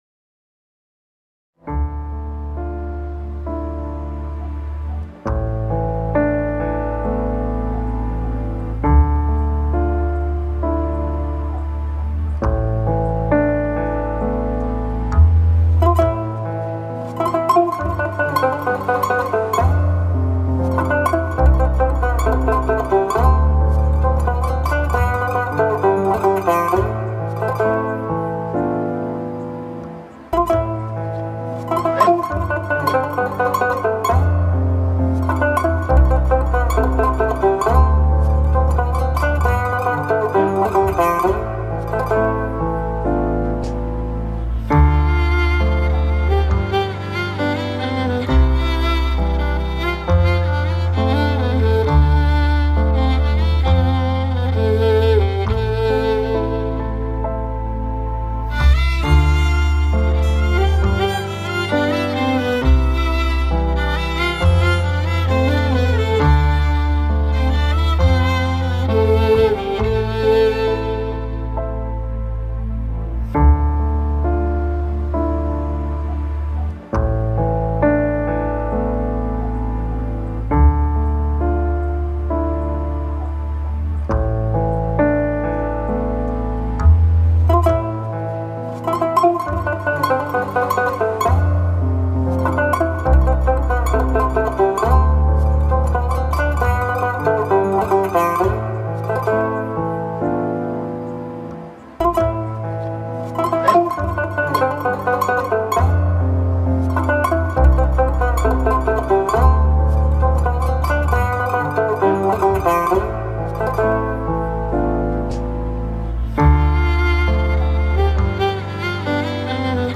duygusal hüzünlü üzgün fon müziği.